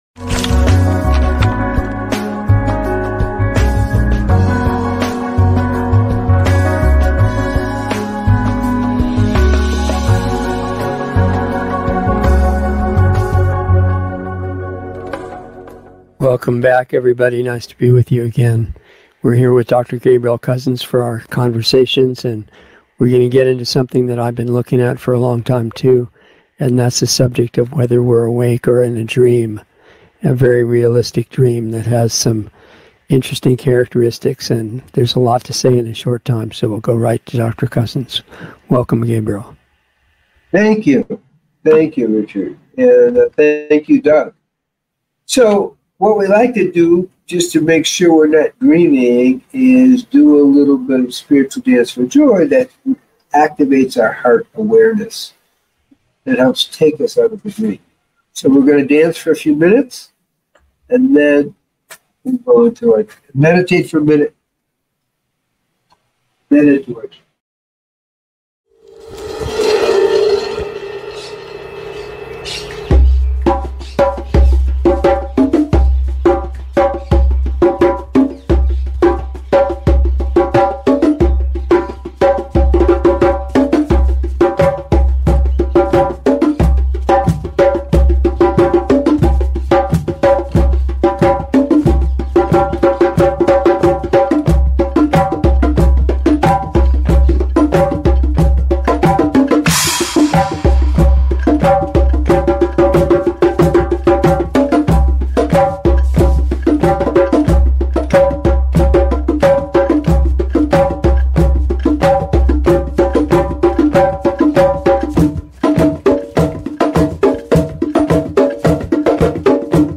Awakening From The Nightmare - Dialogs
A new LIVE series